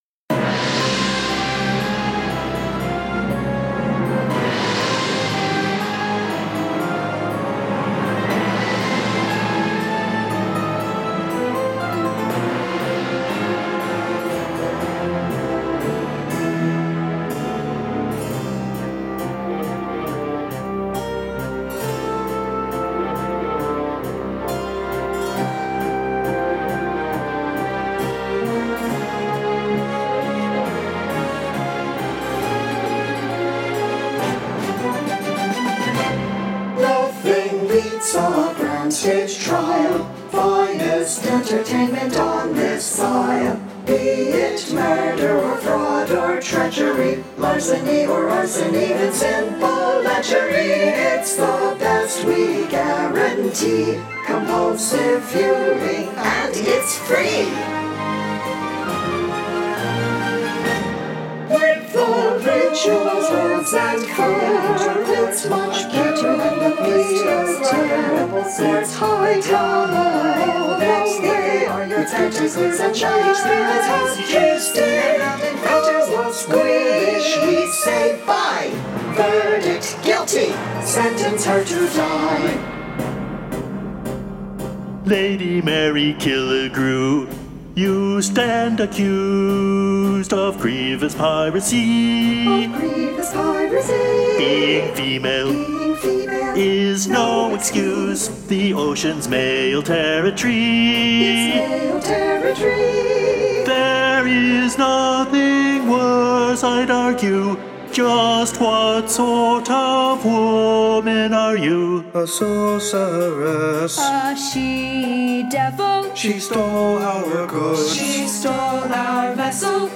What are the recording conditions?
orchestration added 2/7/20